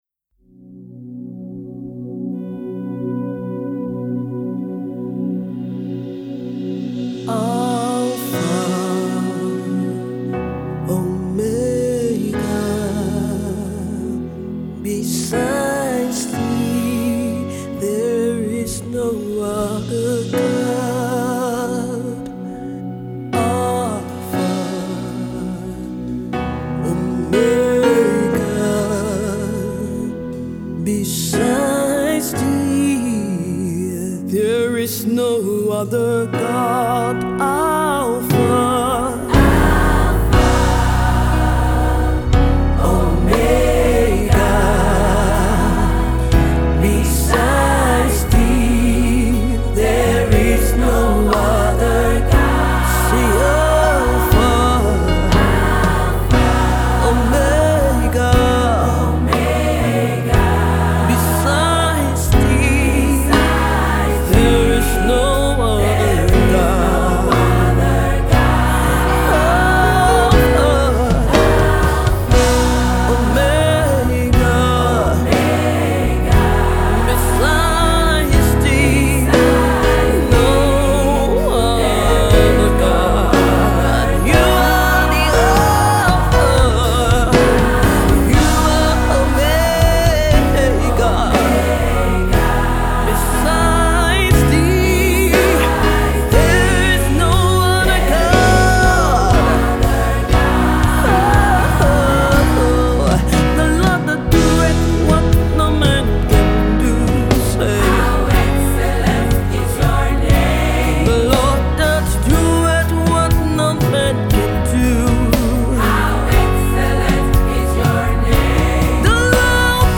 delivery comes with raw passion, excellence and anointing.